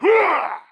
attack_1.wav